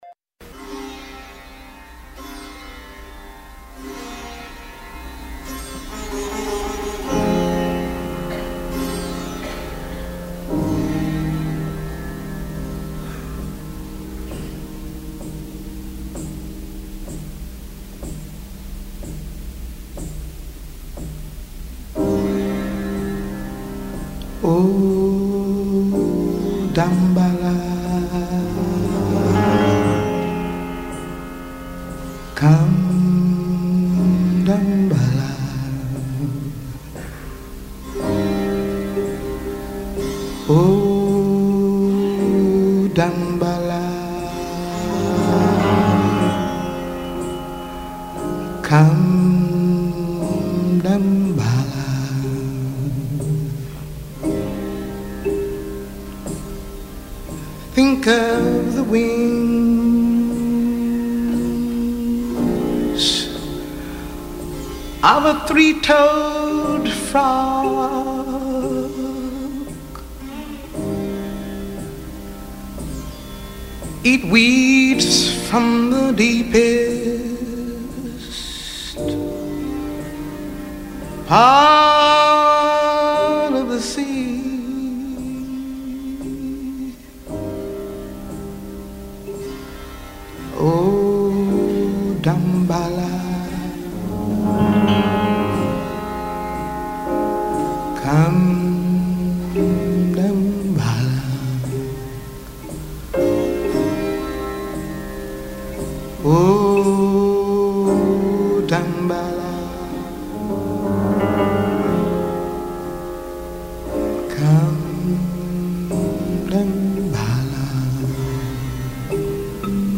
Musica a 360°, viva, legata e slegata dagli accadimenti.